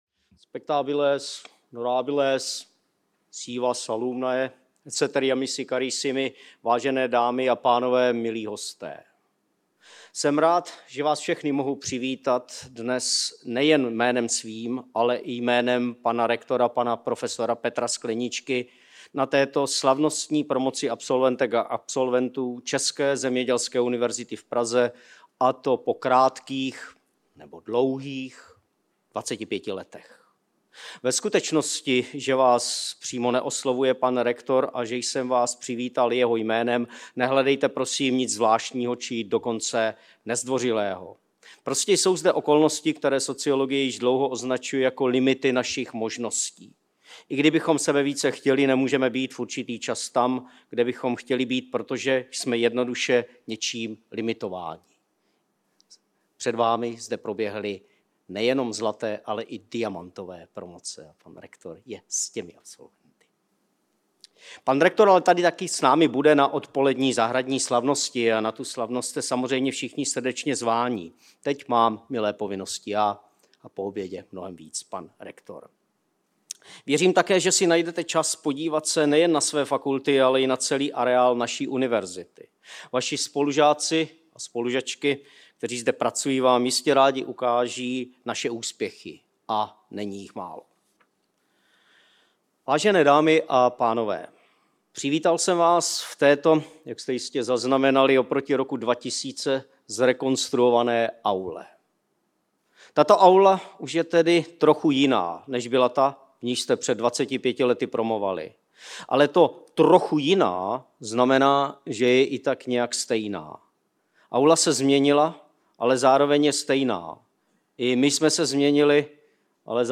Zahradní slavnost: Souznění generací spojených s Českou zemědělskou univerzitou - Česká zemědělská univerzita
proslov_01.mp3